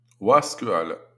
Plik audio z wymową.